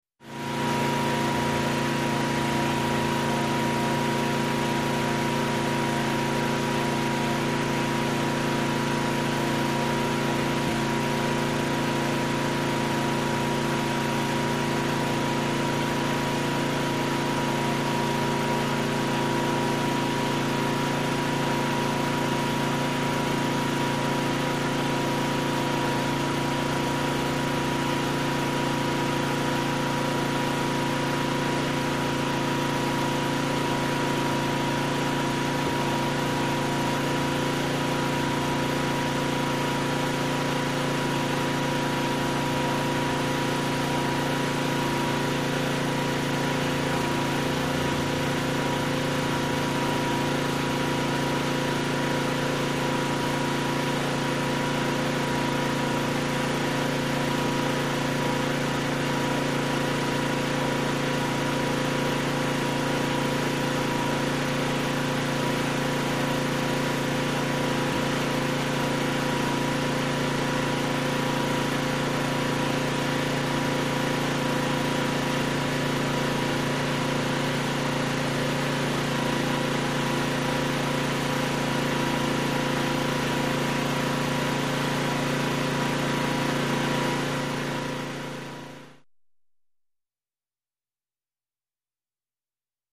Lawnmower
Lawnmower Engine Steady, 8 Horsepower Briggs And Stratton, With Medium Fast R.P.M.